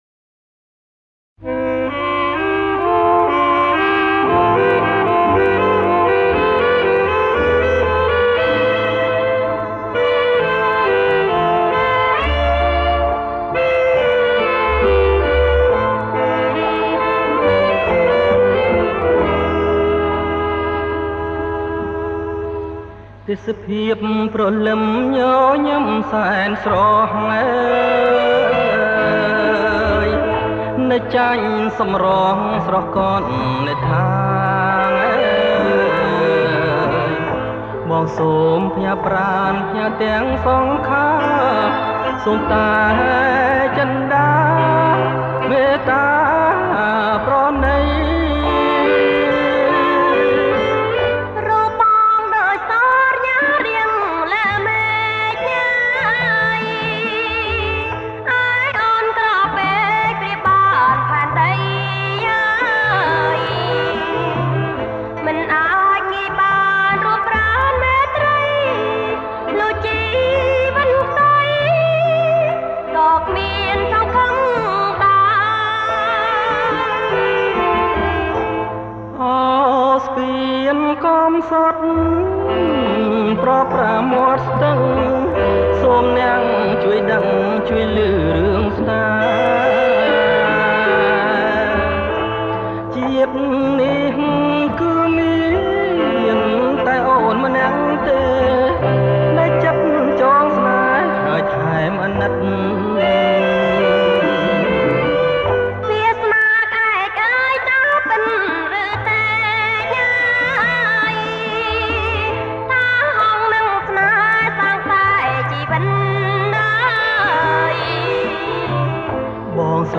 • ប្រគំជាចង្វាក់ Bolero